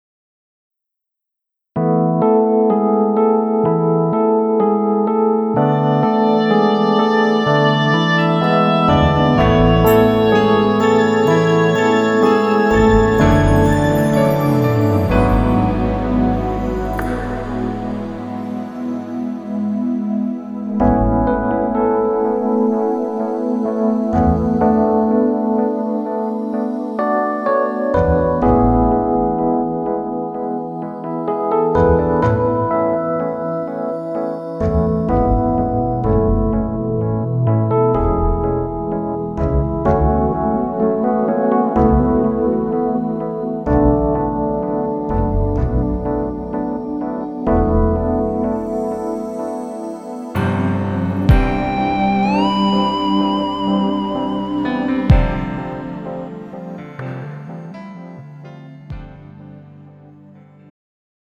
음정 -3키
장르 축가 구분 Pro MR